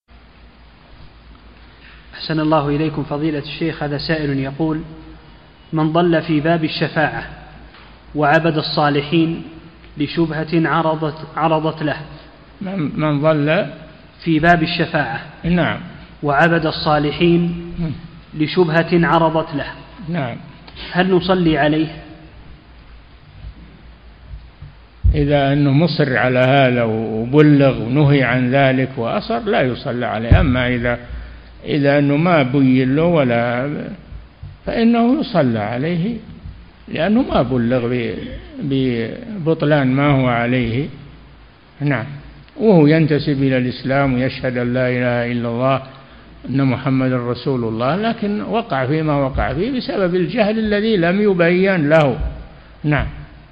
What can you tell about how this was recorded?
Original is from this lecture (Sharh Fath al-Majeed) at 55m:30s, dated 17/04/1437AH (see here).